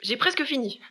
VO_ALL_Interjection_08.ogg